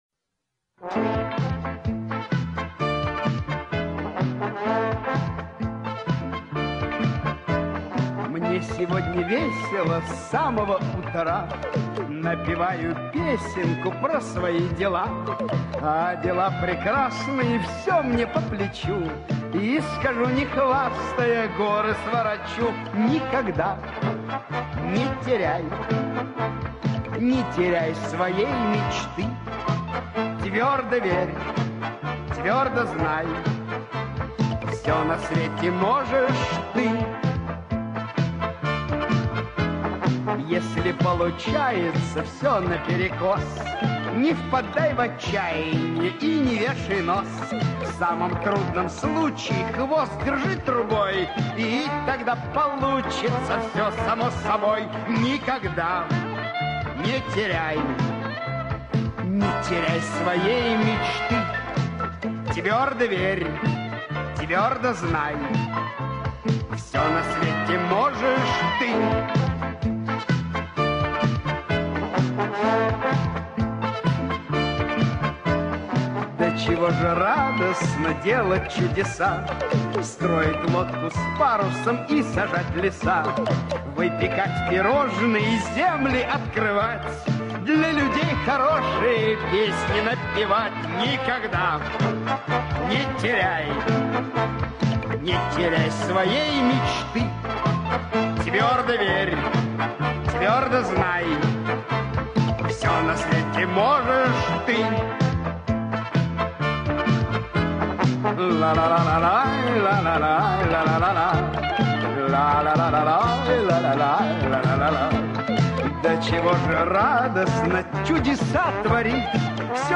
Я тебе лучше промурчу! Мур-р-р!